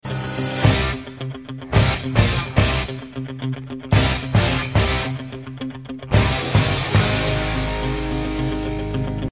two stroke motorcycle & scooter